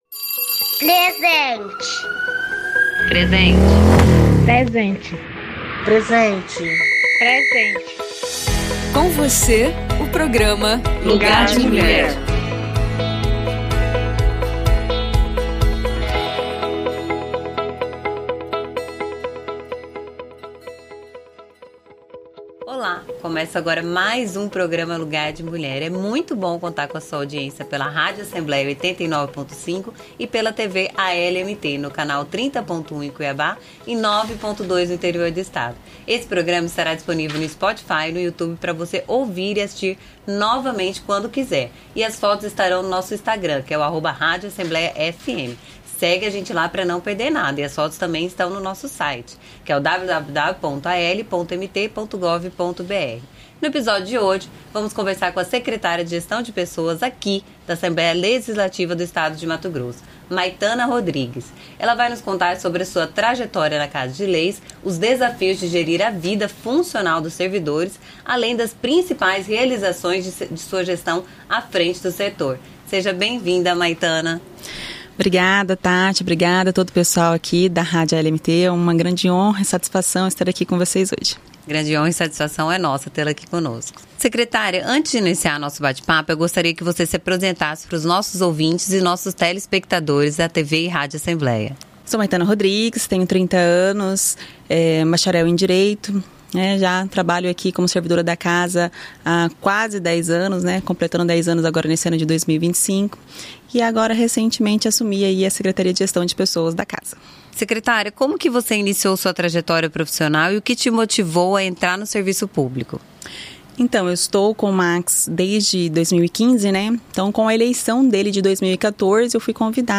E a mais nova edição do programa Lugar de Mulher entrevista a convidada sobre a atuação das equipes de trabalho da Assembleia e sobre planos futuros voltados para servidores.